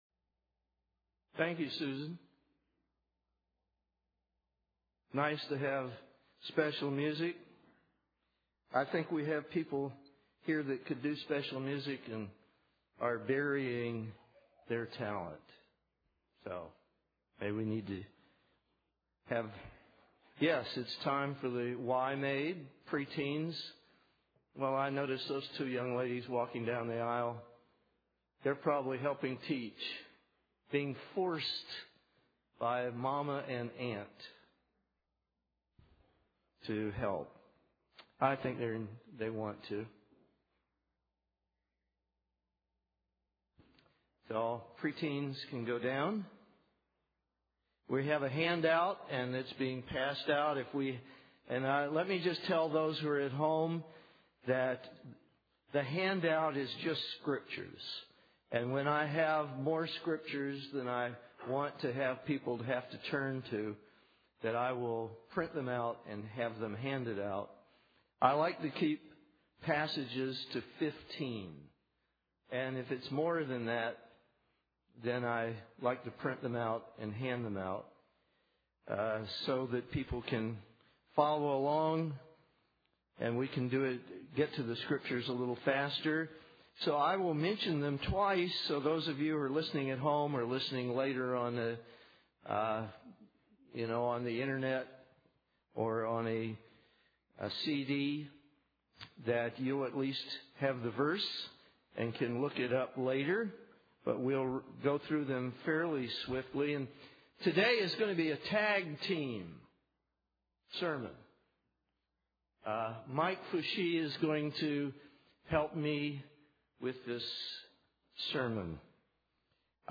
Should we exercise our second amendment rights and arm our home with an array of guns, semi-automatic or otherwise? The central message of the sermon is that you will never have better protection than you will have with God.
Given in Nashville, TN